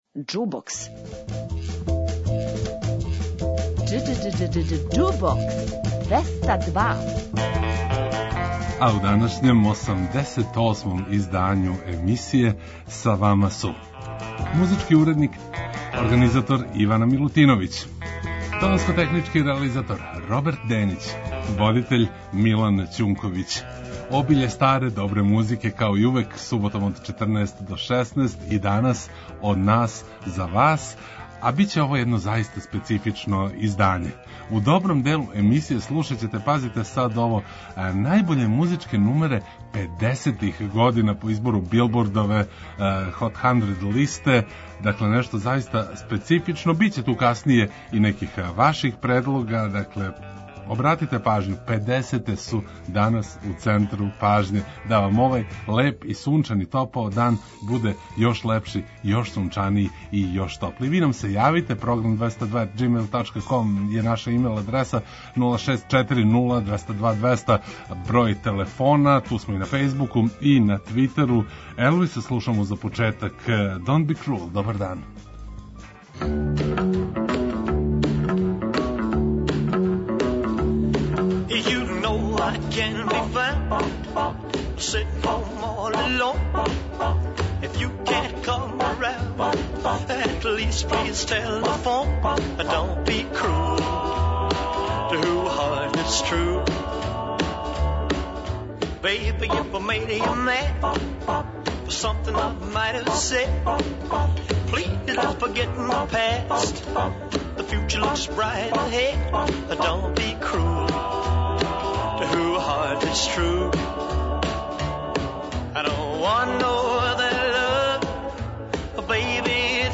преузми : 28.54 MB Џубокс 202 Autor: Београд 202 Уживајте у пажљиво одабраној старој, страној и домаћој музици.